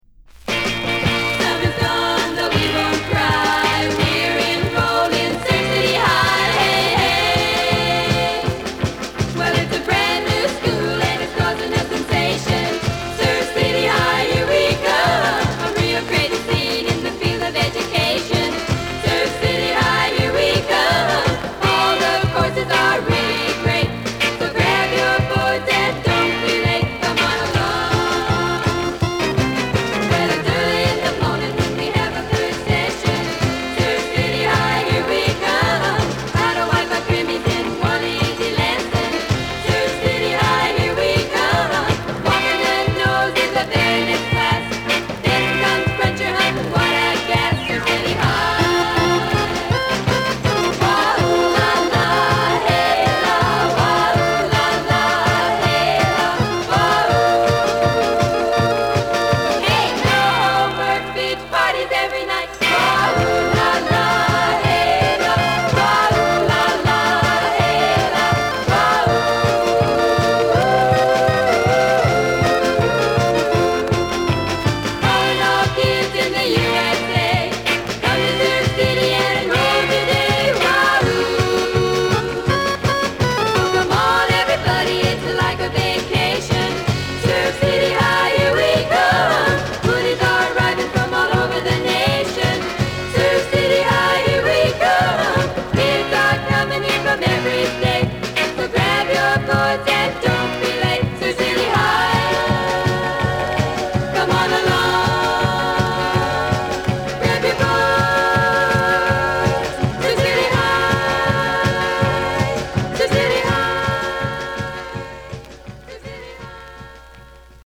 たどたどしいヴォーカルが思わず微笑んでしまうロウ・フィメイル・サーフ・コーラス・サウンドで最初から最後まで押しまくる。
バックグラウンドノイズはいります。